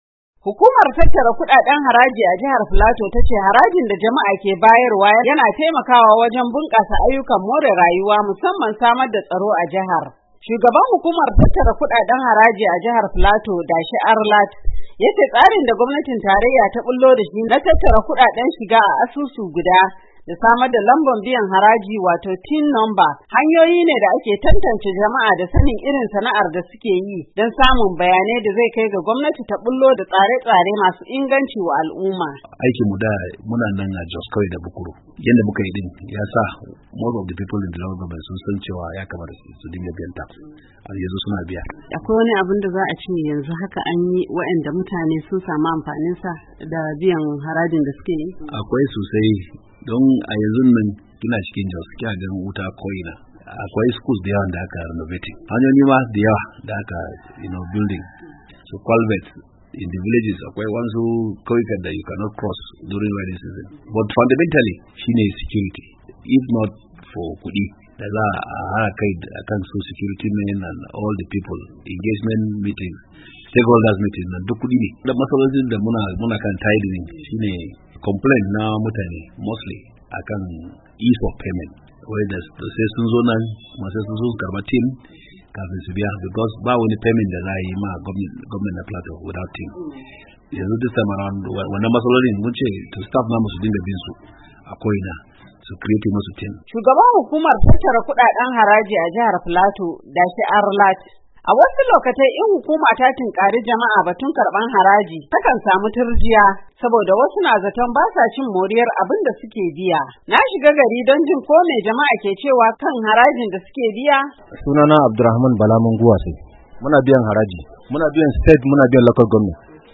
A hirarsa da Muryar Amurka, shugaban hukumar tattara kudaden haraji a jihar Filato, Dashe Arlat ya ce tsarin da gwamnatin tarayya ta bullo da shi na tattara kudaden shiga a asusu guda da samar da lambar biyan haraji wato TIN Number, hanyoyine da ake tantance jama’a da sanin sana’ar da suke yi don taimaka wa gwamnati da bayanan da za ta yi tsare-tsare da zasu inganta rayuwar al’umma.
Wasu 'yan Najeriya sun bayyanawa Muryar Amurka ra'ayoyinsu game da wannan kudade na haraji da gwamnati take karba.